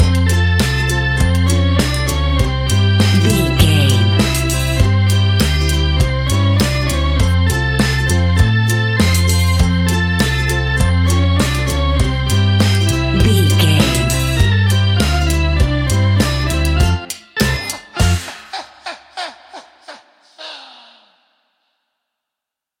In-crescendo
Thriller
Aeolian/Minor
ominous
suspense
haunting
eerie
spooky
instrumentals
horror music
Horror Pads
horror piano
Horror Synths